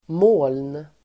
Uttal: [må:ln]